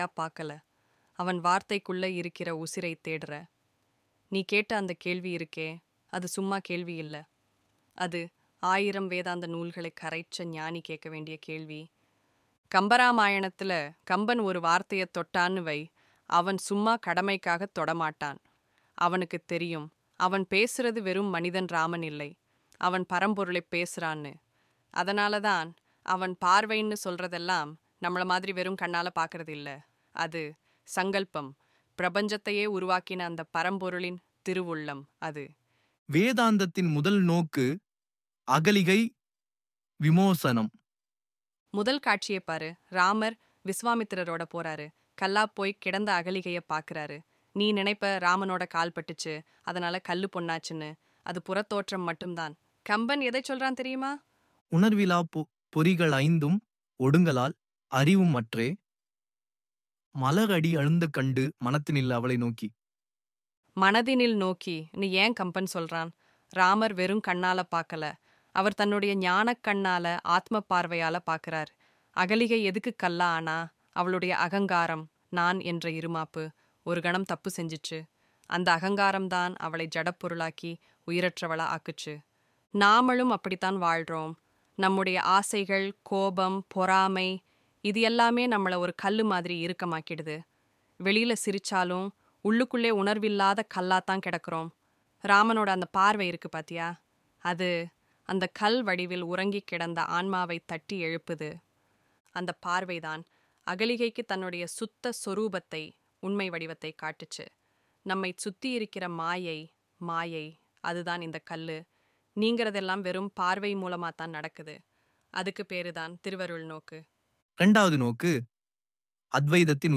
kambannokku-audiobook-1.mp3